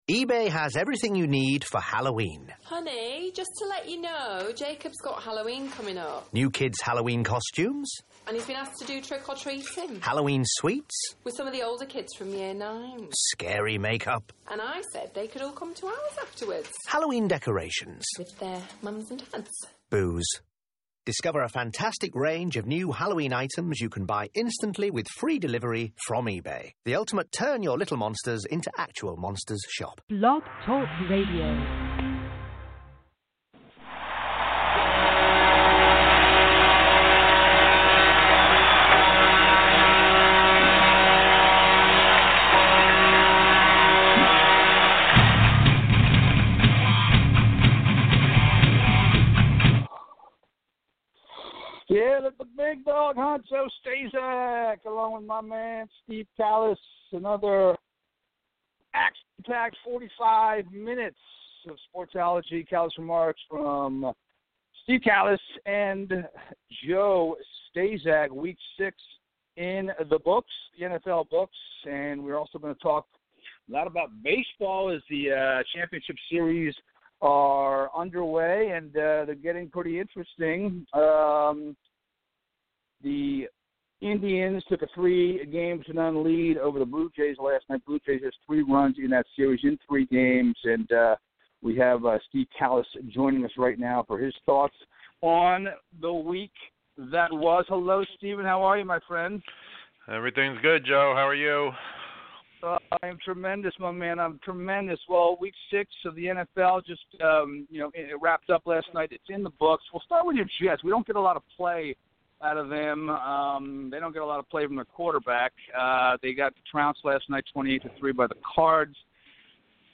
2nd level sports talk show hilighting the ever-changing sports landscap